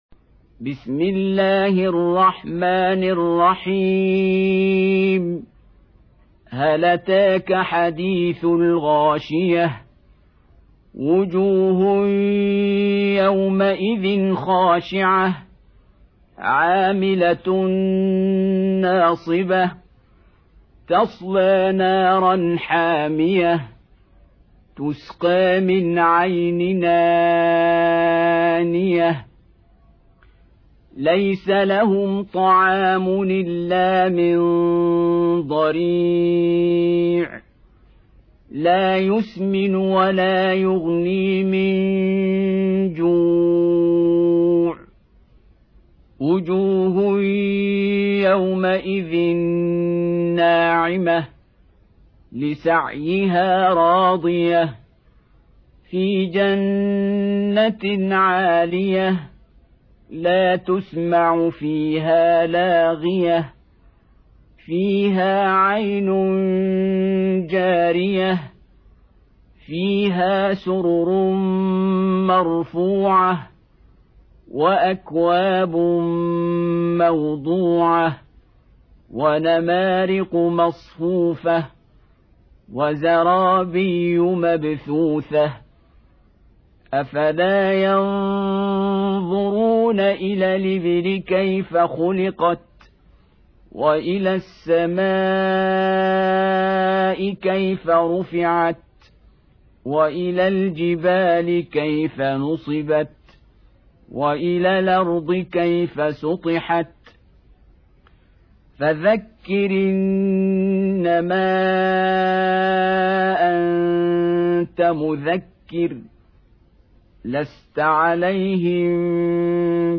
Surah Repeating تكرار السورة Download Surah حمّل السورة Reciting Murattalah Audio for 88. Surah Al-Gh�shiyah سورة الغاشية N.B *Surah Includes Al-Basmalah Reciters Sequents تتابع التلاوات Reciters Repeats تكرار التلاوات